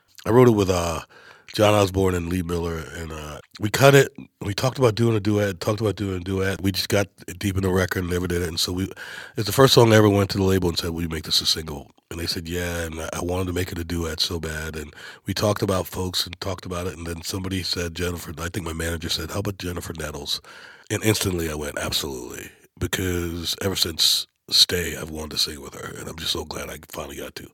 Darius Rucker talks about wanting to make "Never Been Over" a duet.